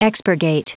Prononciation du mot expurgate en anglais (fichier audio)
expurgate.wav